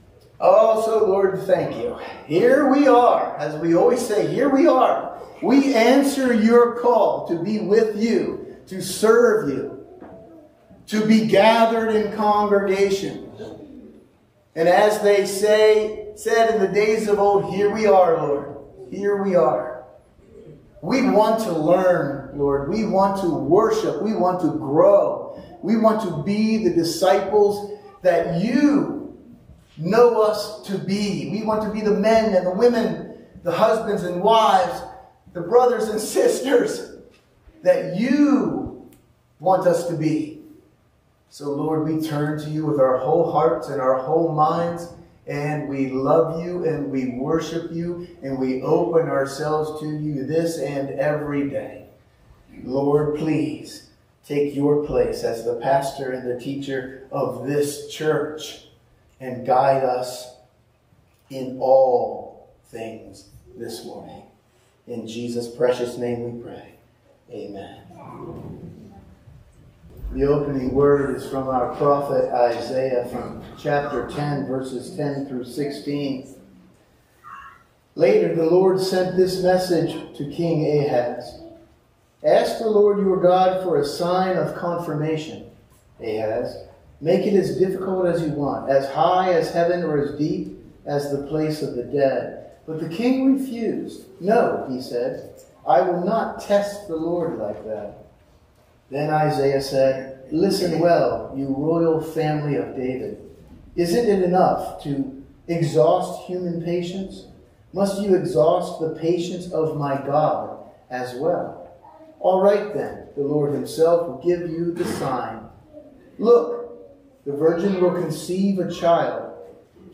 Join us for the Sunday morning service at the Churchtown Church of God.